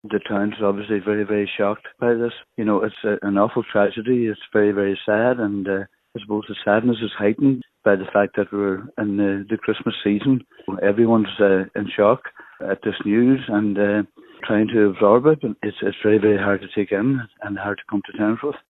Cathaoirleach of the Letterkenny Milford Municipal District, Councillor Jimmy Kavanagh, says everyone in the locality is in shock: